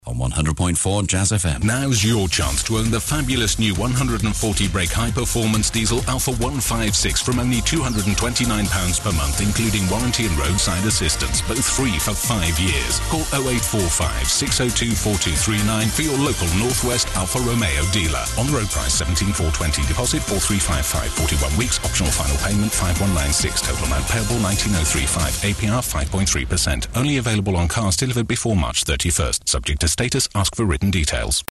Sprecher englisch (uk, british english).
britisch
Sprechprobe: Industrie (Muttersprache):